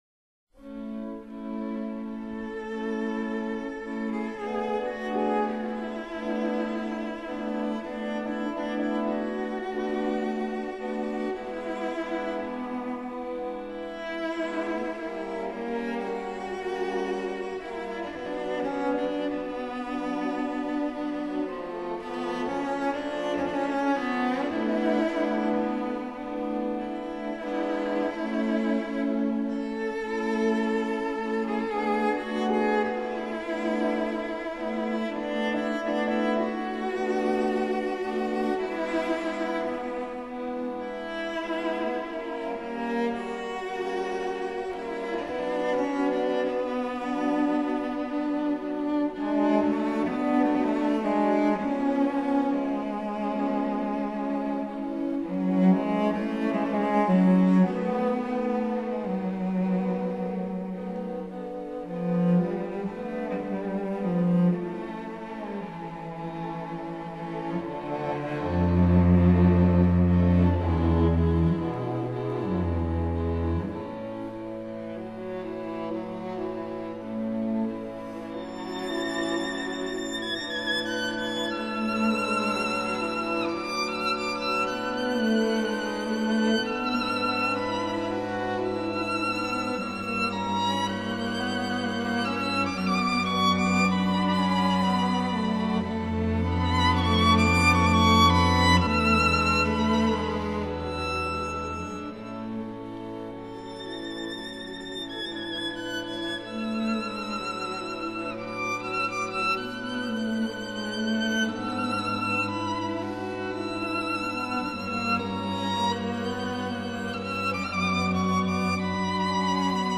A大調第一號弦樂四重奏
D大調第二號弦樂四重奏
violin
viola
cello